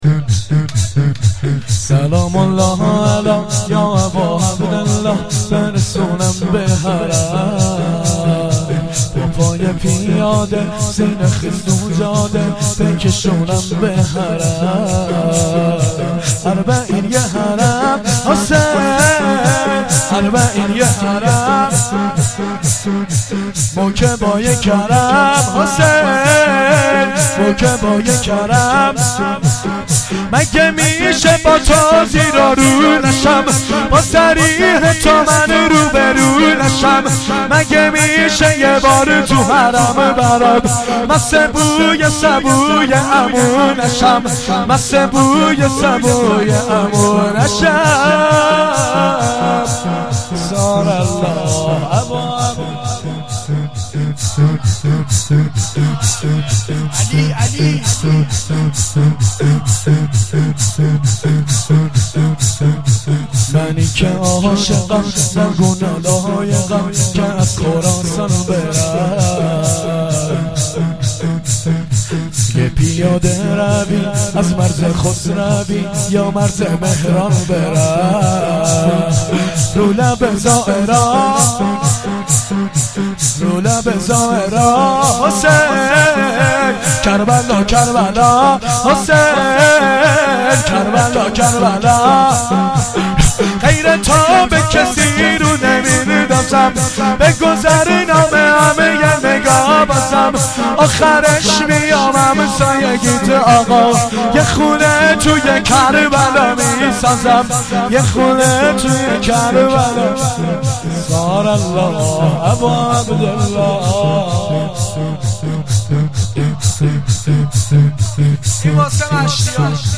شور
مراسم بدرقه زائران اربعین